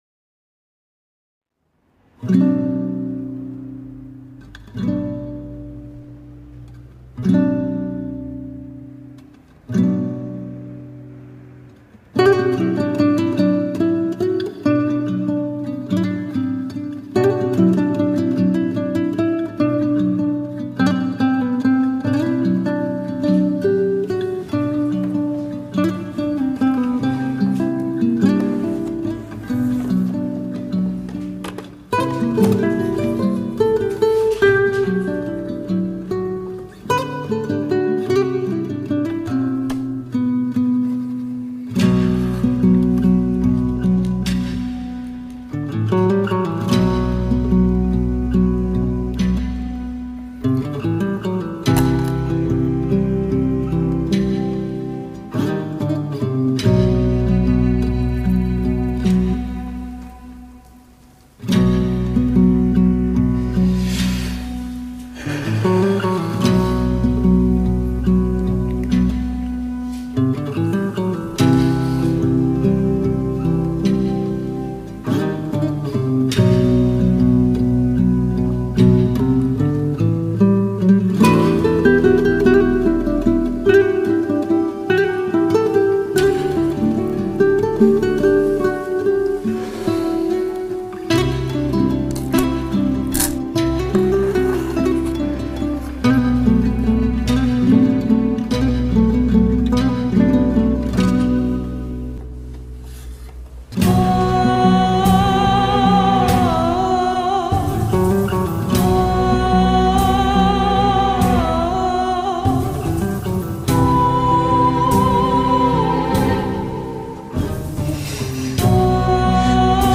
tema dizi müziği, duygusal hüzünlü üzgün fon müziği.